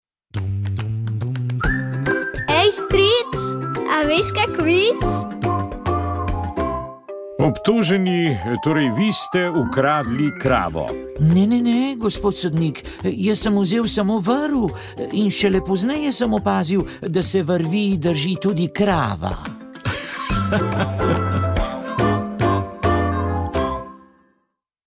Molitev je vodil ljubljanski nadškof Stanislav Zore.